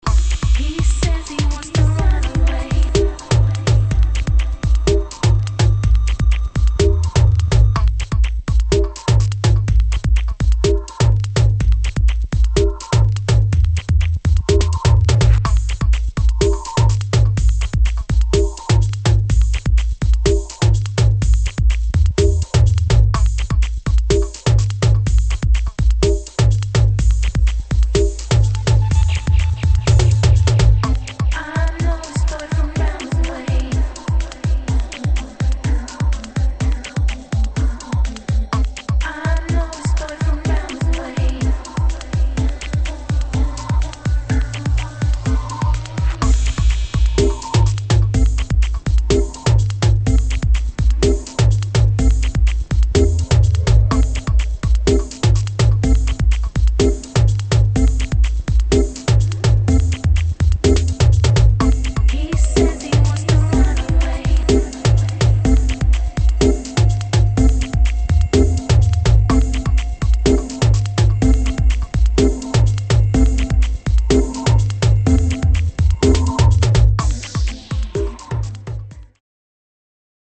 [ BASS | UK GARAGE ]